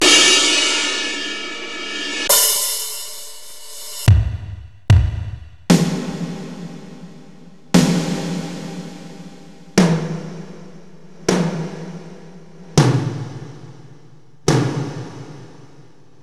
Roland_S-550_Stereo Drums1.wav